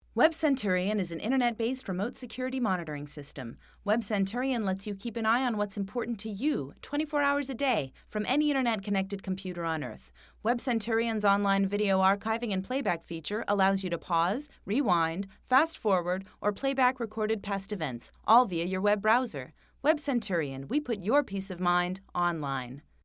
Sprecherin englisch.
Kein Dialekt
Sprechprobe: Werbung (Muttersprache):
voice over english (us).I also speak spanish and German.